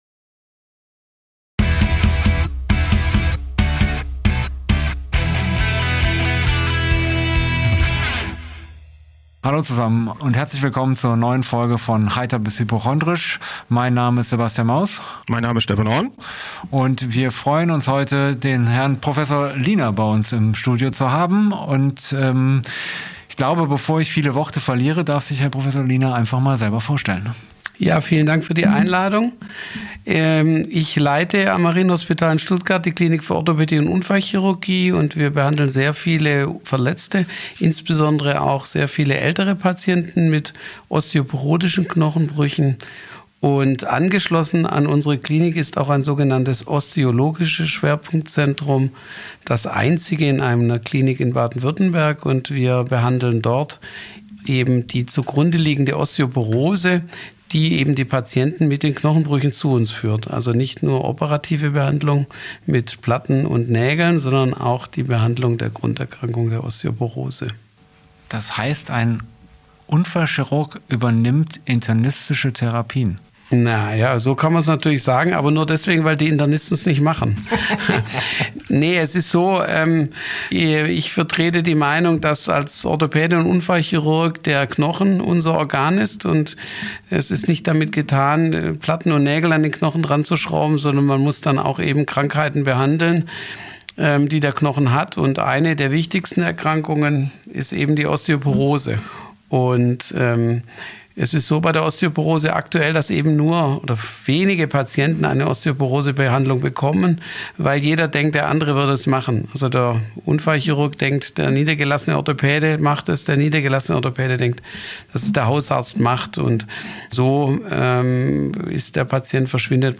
Wir erfahren, dass Osteoporose oft unbemerkt bleibt, bis ein Knochenbruch auftritt, und wie wichtig es ist, die Krankheit frühzeitig zu erkennen und zu behandeln. Unser Experte erklärt, wie Osteoporose entsteht, welche Risikofaktoren es gibt und wie man sich durch Ernährung, Training und Medikamente schützen kann. Er bespricht auch, wann eine Knochendichtemessung sinnvoll ist und welche Behandlungsmöglichkeiten es gibt.